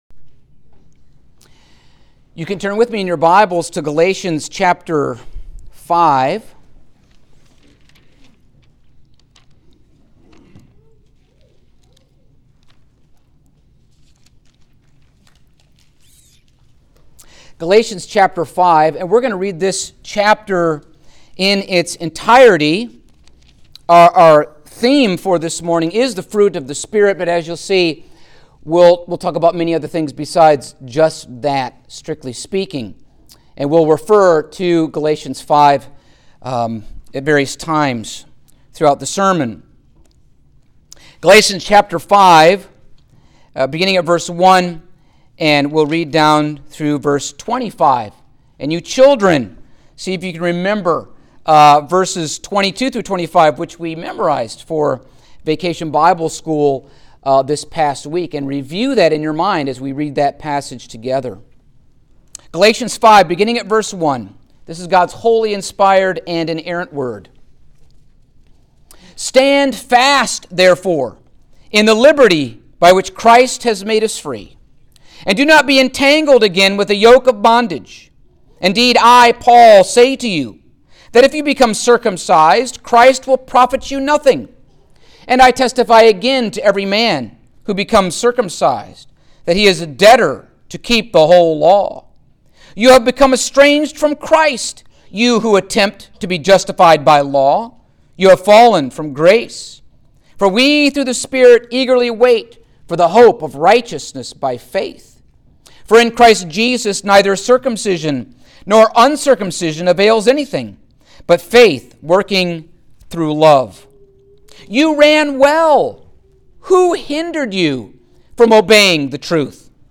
Passage: Galatians 5:22-23 Service Type: Sunday Morning